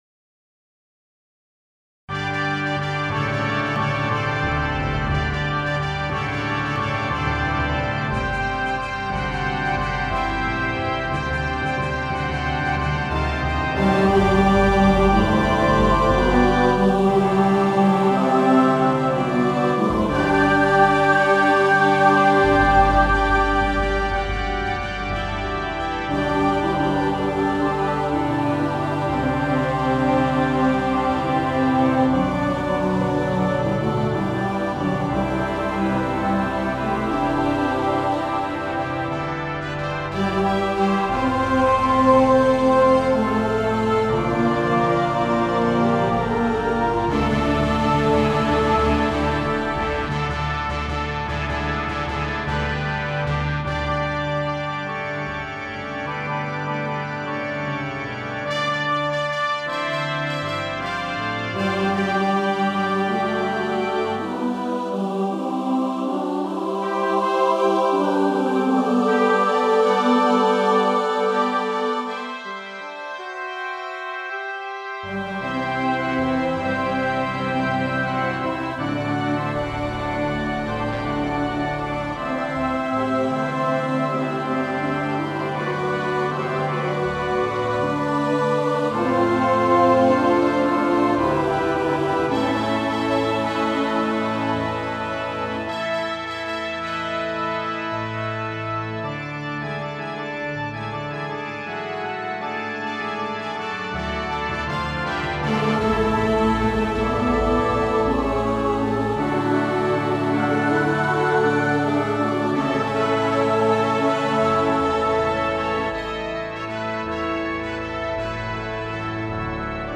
• Accompaniment: Horn, Timpani, Trombone, Trumpet, Tuba
• Season: Easter